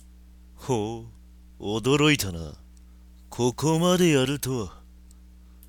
RPG戦闘終了後キャラクター台詞です。
作った当時に出していた声を忘れたので、2通り録ってみました。
しっかし、マイクの集音力高すぎです。
マウスのクリック音まで入ってますよ。